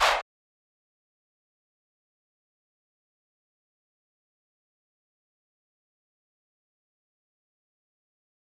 Redd Chant.wav